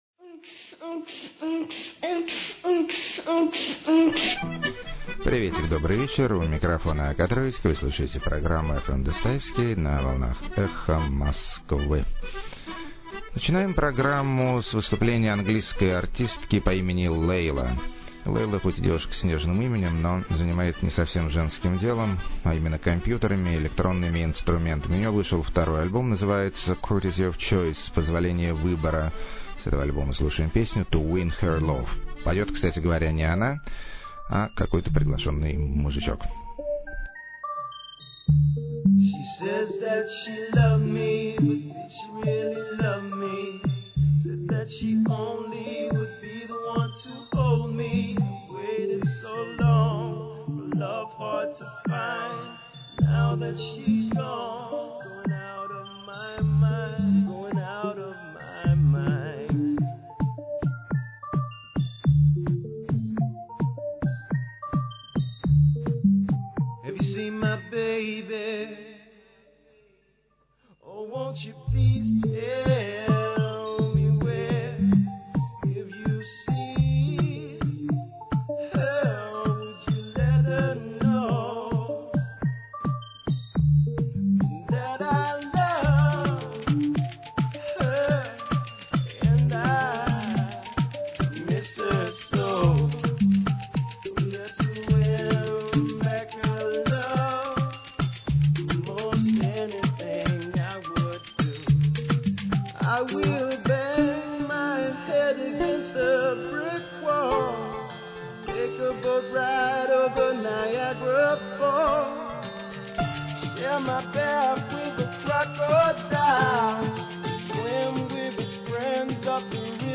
Неожиданно Мелодично Для Черной Африки.
Акустический Песенный Дуэт Вдруг Попал В Модную Струю.
Психо-пост-маримба.
Южно-православный Фольклор.
Виртуозные Компьютерные Ритм-манипуляции.
Бретонско-нормандский Кельто-панк.] 13.
Гениальный Психо-поп.